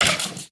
Minecraft Version Minecraft Version snapshot Latest Release | Latest Snapshot snapshot / assets / minecraft / sounds / mob / stray / hurt4.ogg Compare With Compare With Latest Release | Latest Snapshot
hurt4.ogg